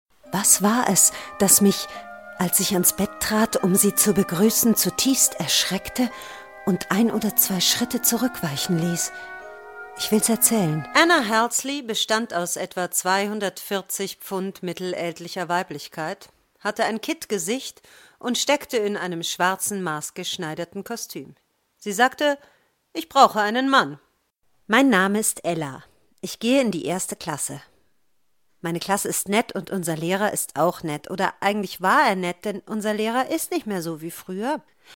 warme Stimme für Hoerbuecher, Reportagen, Dokumentationen, Voice over, Werbung, Computerspiele, Mutimediaprojekte, Sprachkurse.
Sprechprobe: Werbung (Muttersprache):